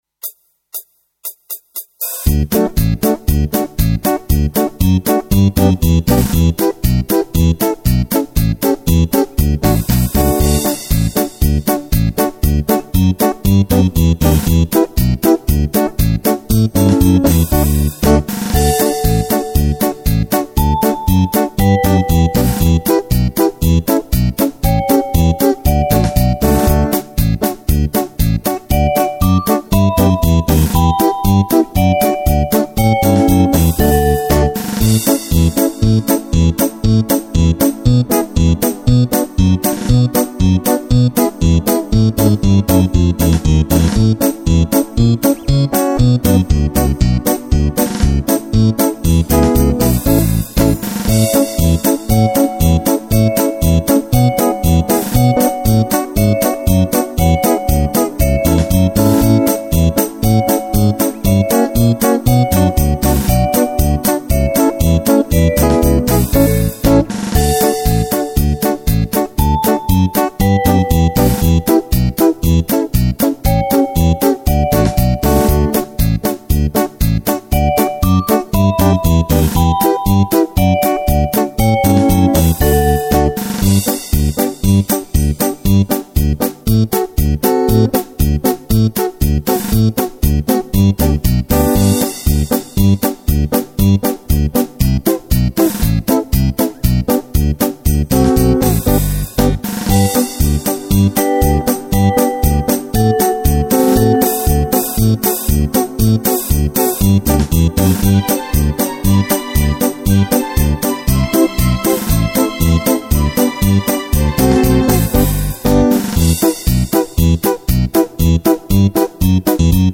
Gran Polka variata per Fisarmonica e Orchestra